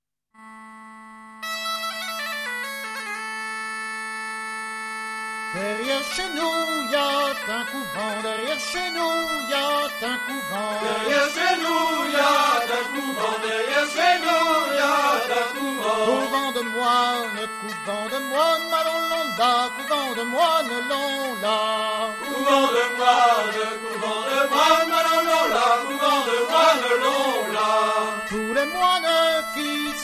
Musique : Traditionnel
Origine : Bretagne
Danse : Bal métayer (Passepied)